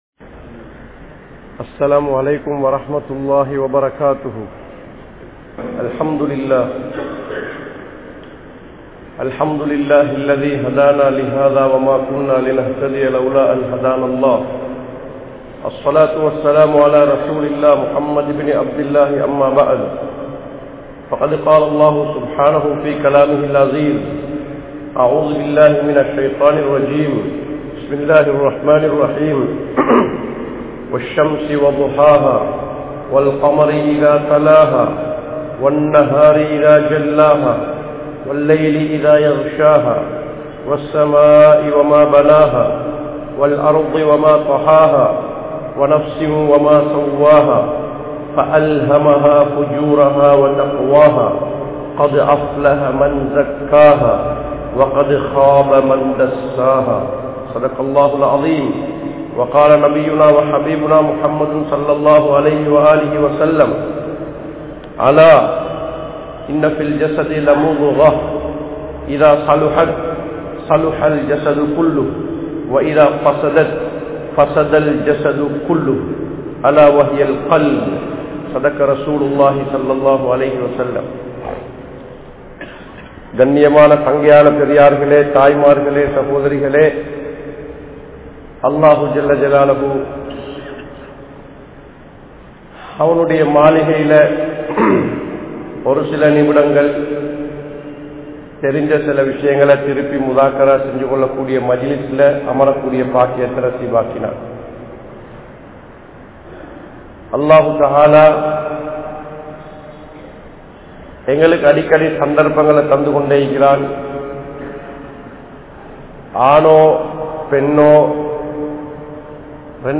Kettup Poana Ullangal (கெட்டுப் போன உள்ளங்கள்) | Audio Bayans | All Ceylon Muslim Youth Community | Addalaichenai